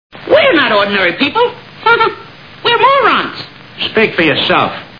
Three Stooges Movie Sound Bites